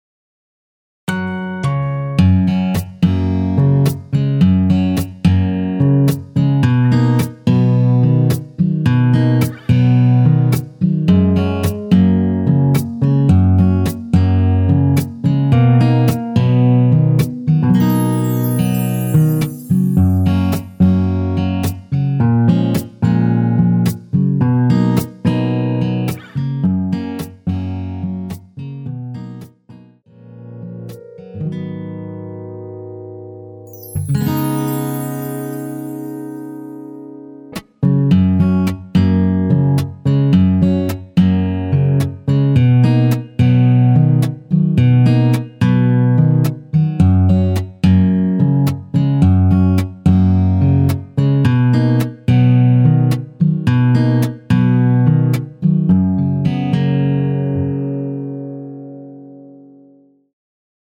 엔딩이 페이드 아웃이라 마지막 음~ 2번 하고 엔딩을 만들어 놓았습니다.(미리듣기 참조)
F#
앞부분30초, 뒷부분30초씩 편집해서 올려 드리고 있습니다.
중간에 음이 끈어지고 다시 나오는 이유는